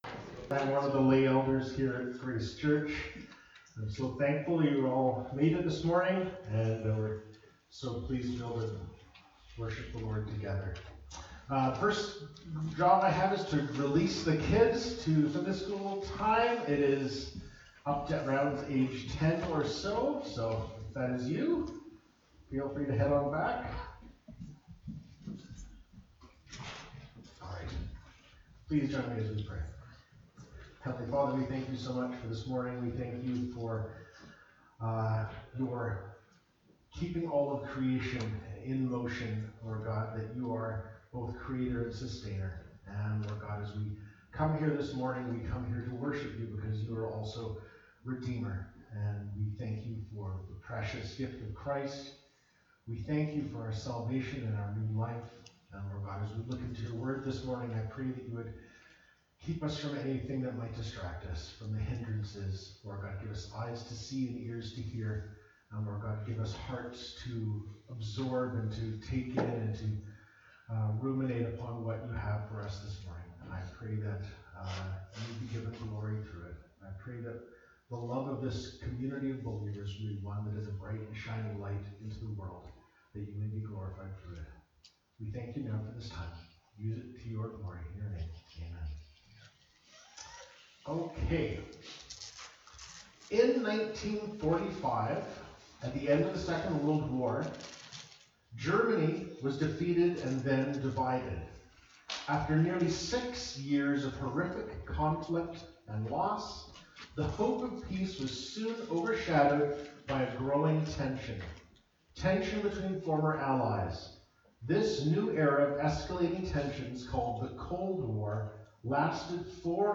Jan 21, 2024 Little Children (John 13:31-38) MP3 SUBSCRIBE on iTunes(Podcast) Notes Discussion Sermons in this Series This sermon was recorded at Grace Church - Salmon Arm and preached in both Salmon Arm and Enderby.